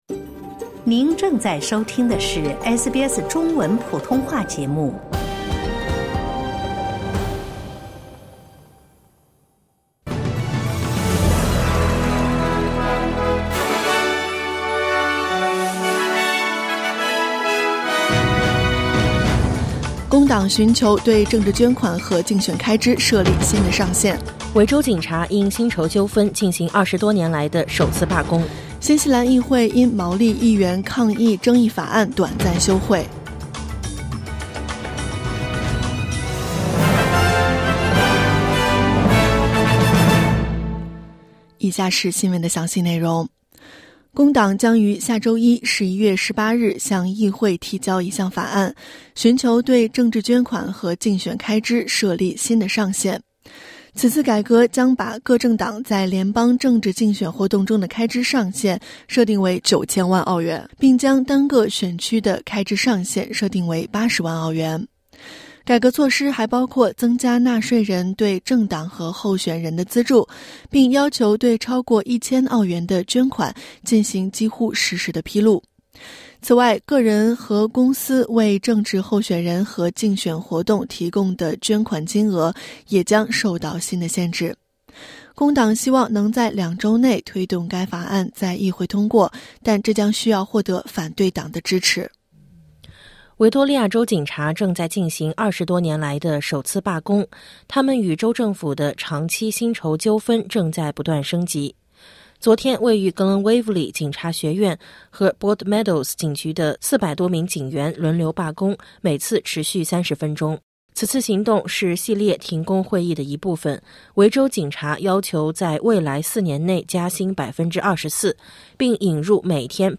SBS早新闻（2024年11月15日）